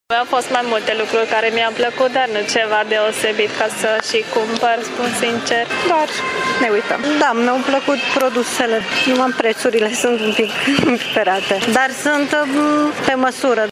Vizitatorii au admirat produsele expuse, dar i-au cam nemulțumit prețurile: